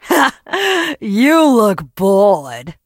jackie_kill_vo_05.ogg